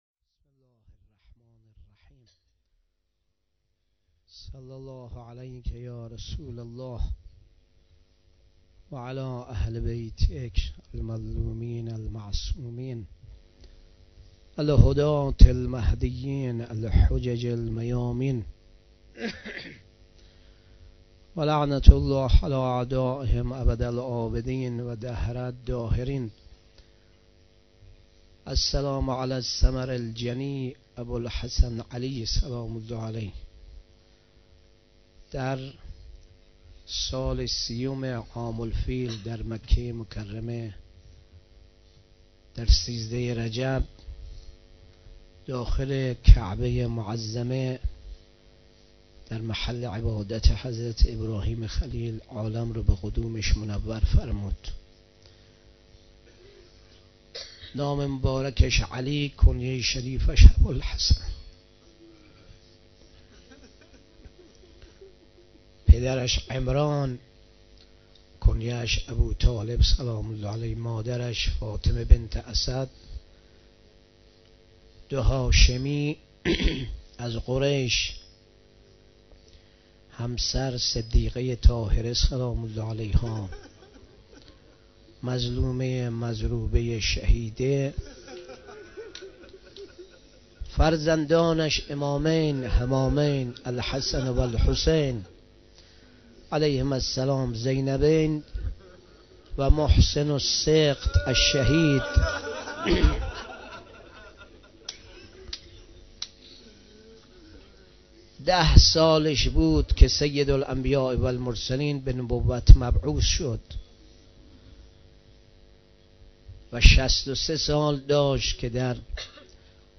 شب بیست و یکم رمضان 96
سخنرانی